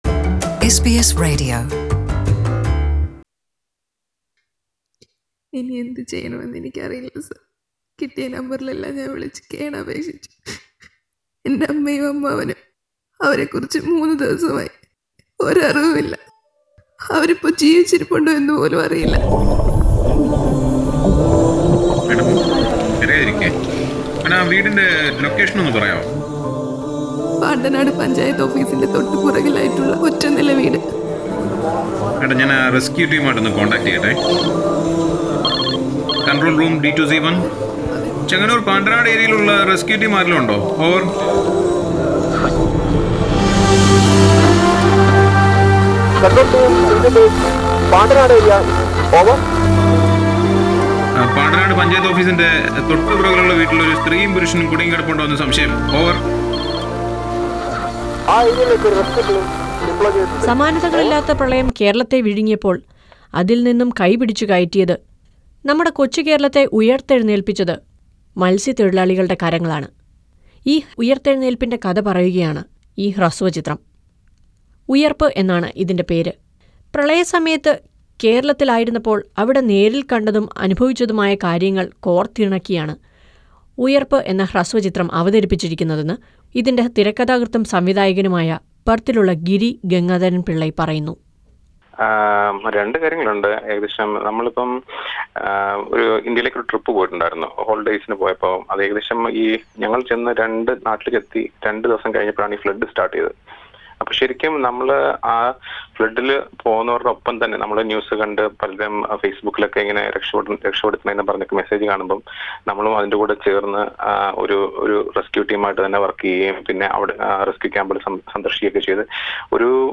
Listen to a report on a Malayalam short film 'Uyirppu' based on the misuse of social media released from Perth.